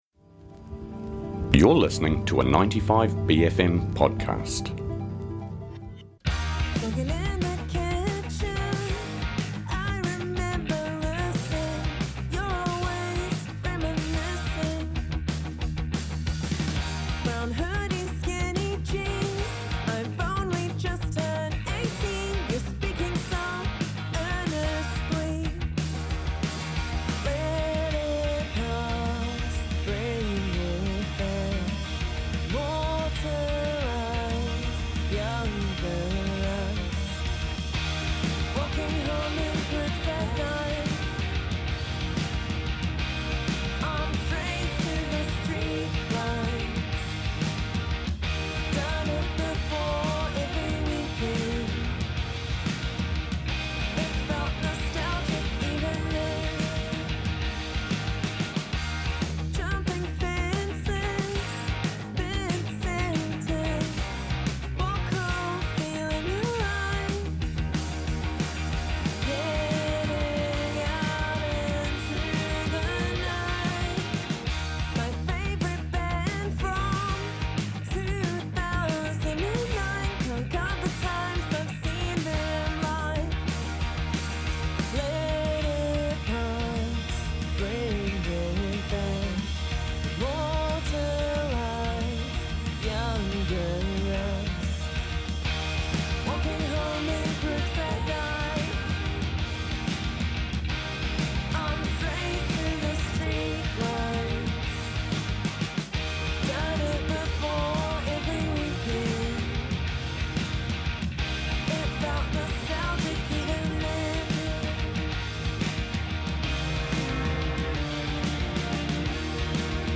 Guest Interviews